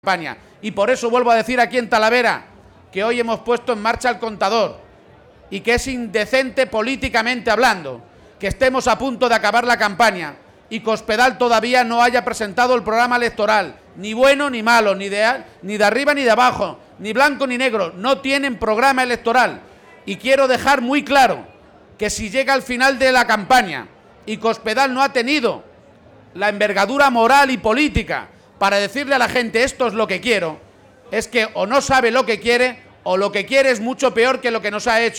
En un acto en la caseta del PSOE en el recinto ferial de Talavera de la Reina (Toledo), García-Page ha reiterado que la candidata del PP a la Junta, María Dolores de Cospedal, «no ha presentado su programa» y no se sabe si es «bueno ni malo, ni blanco ni negro ni de arriba o de abajo», algo que ha insistido en que es «indecente».